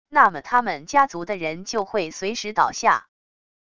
那么他们家族的人就会随时倒下wav音频生成系统WAV Audio Player